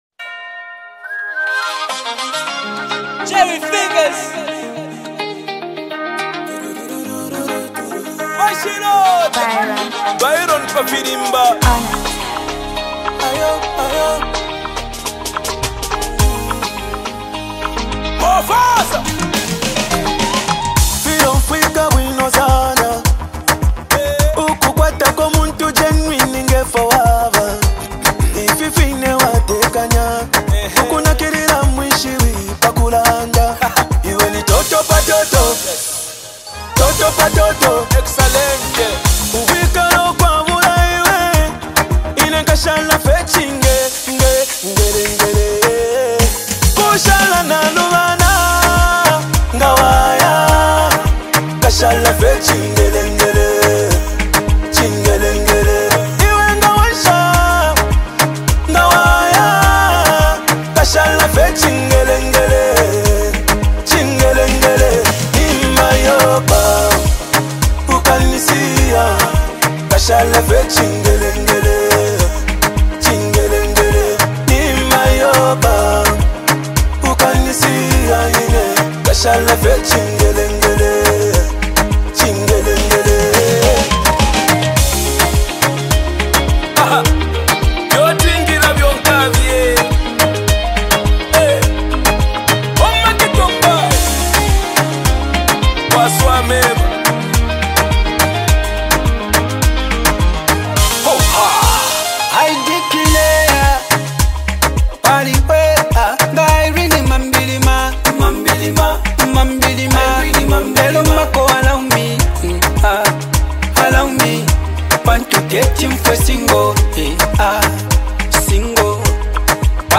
signature soulful delivery
a youthful, melodic energy